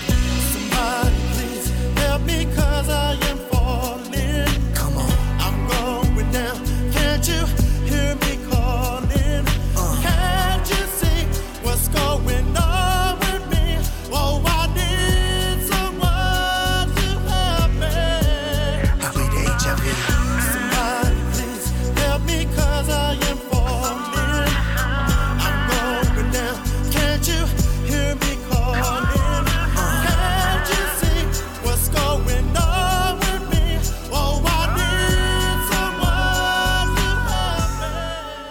• Качество: 320, Stereo
мужской вокал
Хип-хоп
грустные